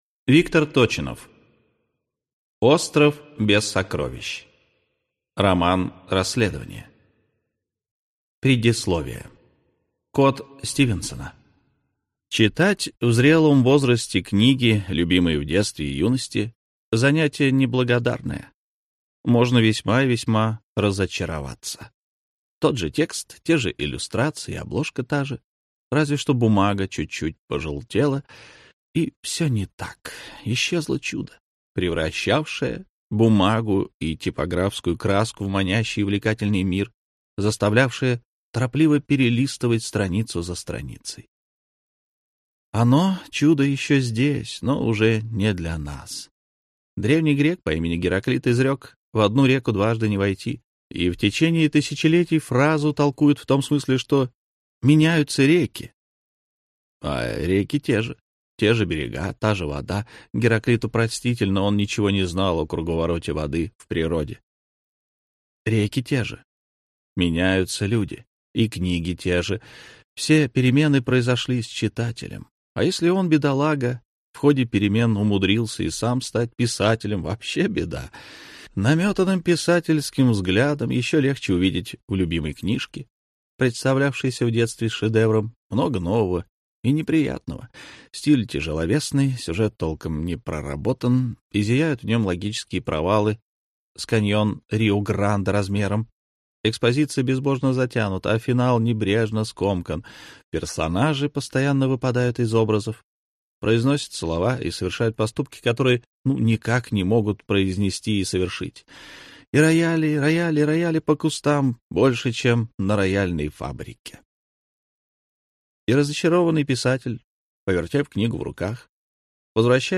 Аудиокнига Остров без сокровищ | Библиотека аудиокниг